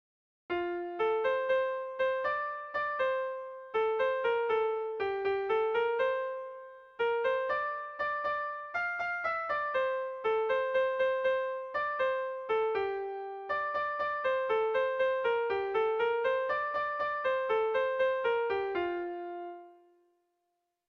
Irrizkoa
ABDE